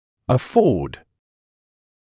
[ə’fɔːd][э’фоːд]позволять себе (в т.ч. финансово)